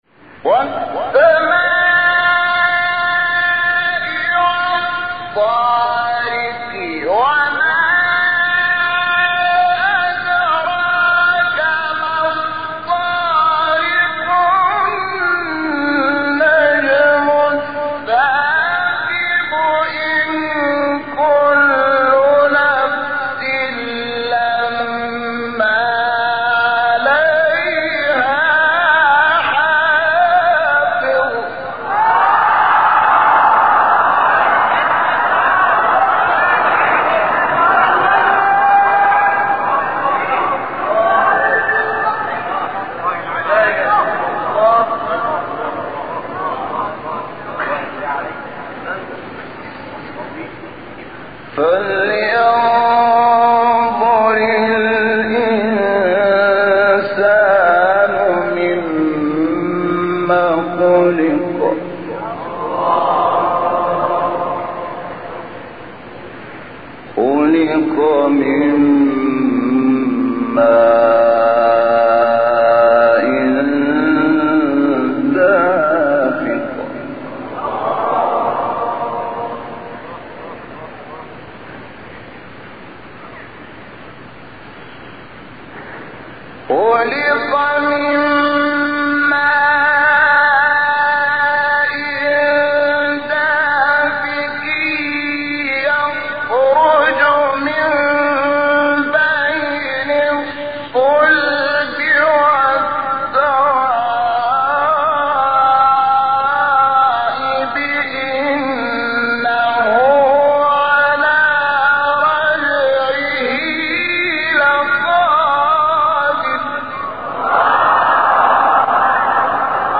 تلاوت زیبای سوره طارق استاد شحات | نغمات قرآن | دانلود تلاوت قرآن